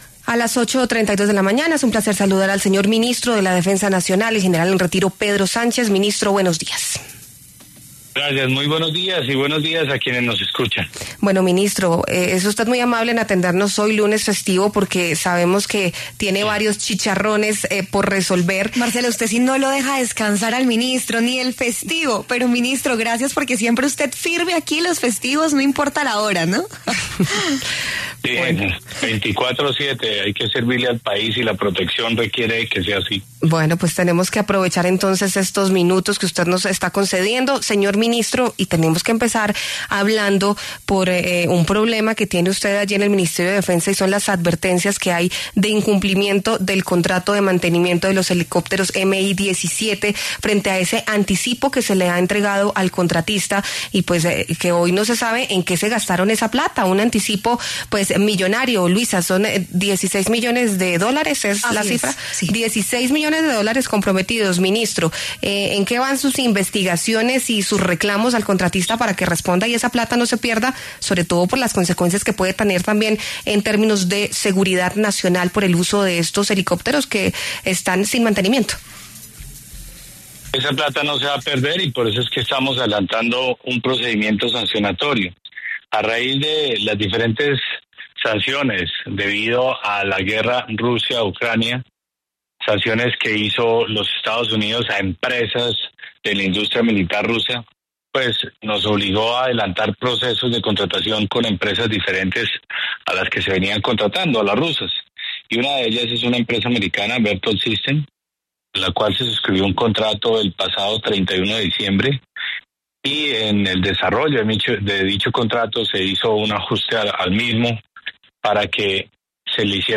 El Ministro de Defensa, Pedro Sánchez, pasó por los micrófonos de La W Radio y dio detalles de lo sucedido con los helicópteros MI-17